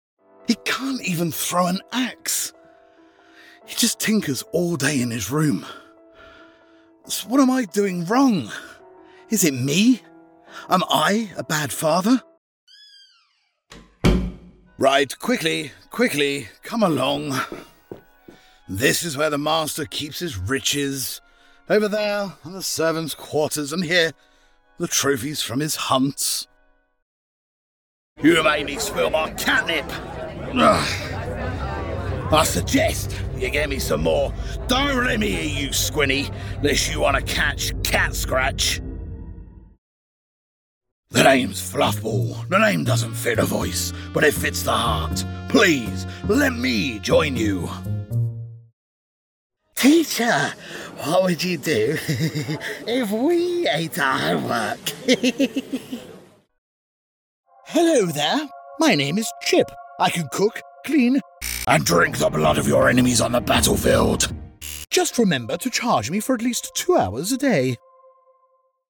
british rp | character
cockney | character